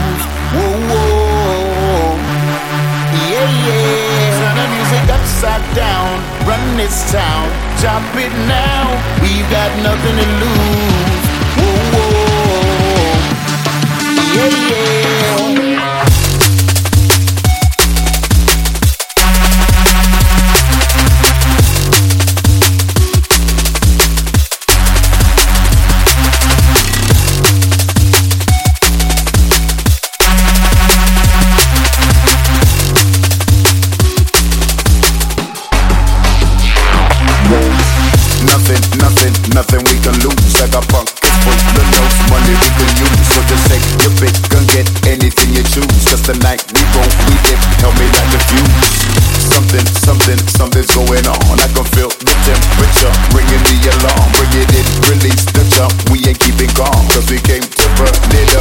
Drum&Bass